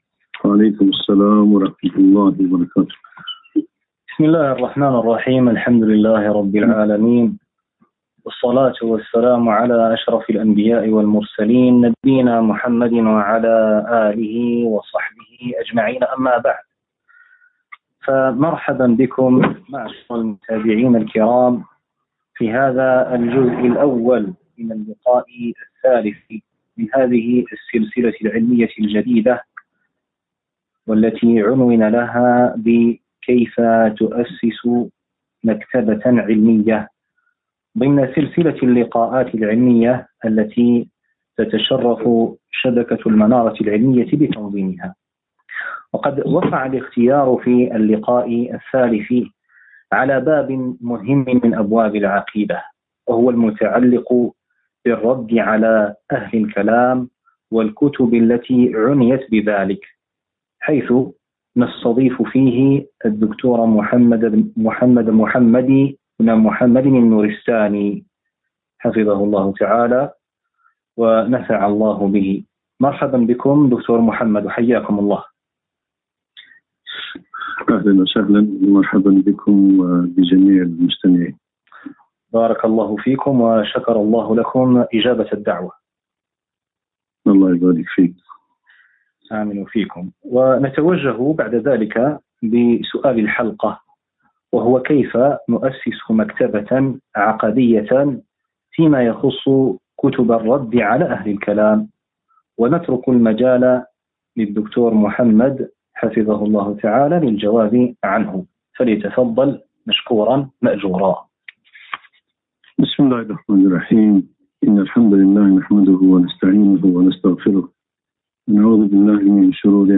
محاضرة - كيف تؤسس مكتبة عقدية (كتب الرد على أهل الكلام)